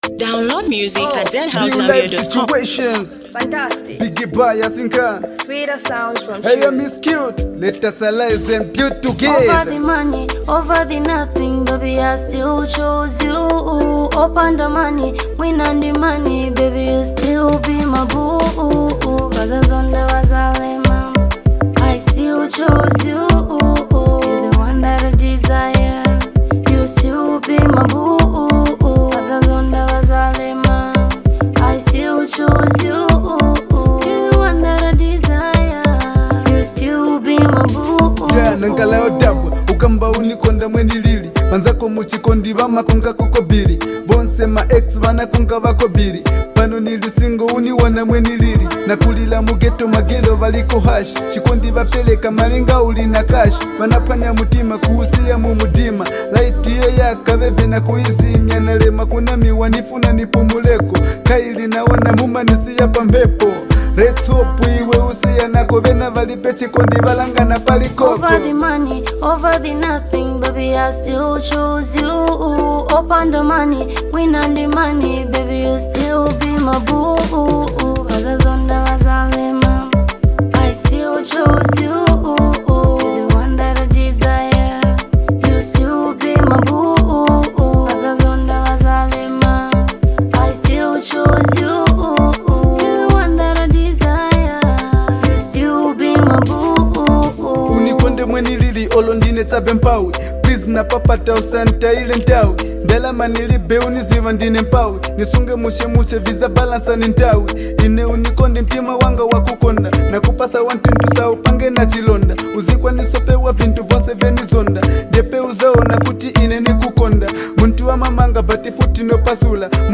Pure vibes and passion in every beat!